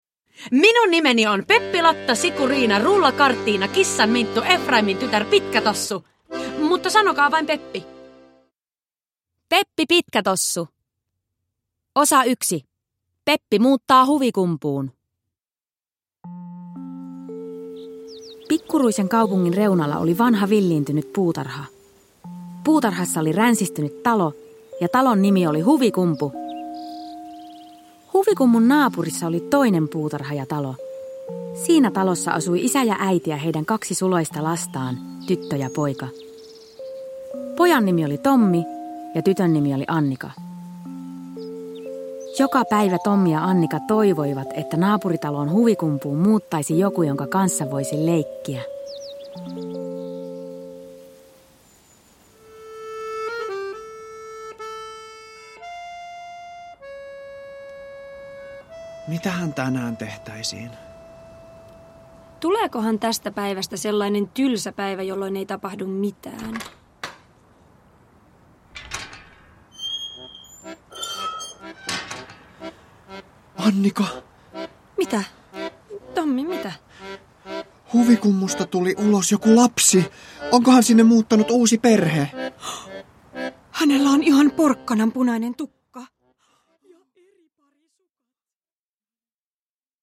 Kaikki on vinksin vonksin näissä iloisissa kuunnelmissa!
Kaikille tuttu Peppi Pitkätossu ilahduttaa nyt eläväisinä ja hauskoina kuunnelmina.